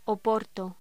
Locución: Oporto
voz